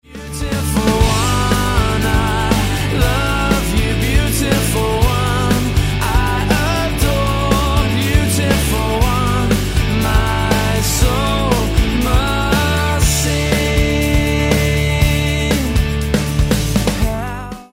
it’s tighter and a bit more aggressive.